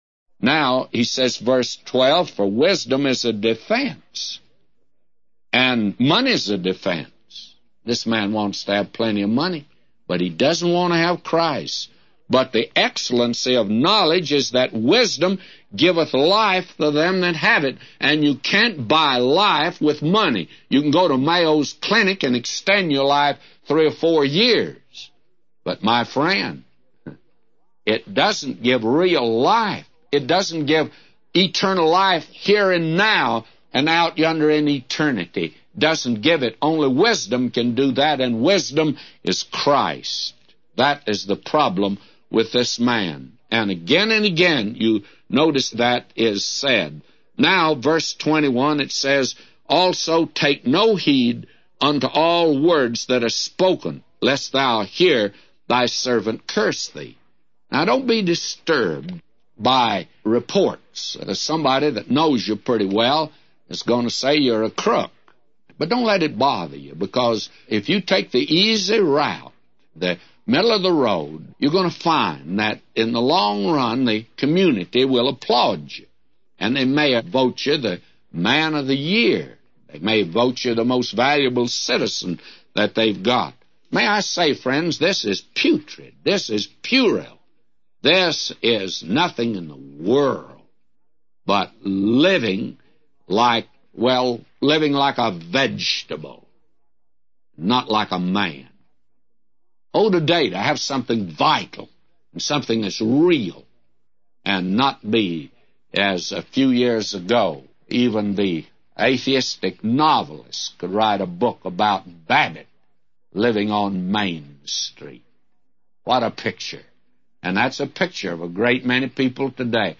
A Commentary By J Vernon MCgee For Ecclesiastes 7:12-999